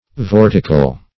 Vortical \Vor"ti*cal\, a.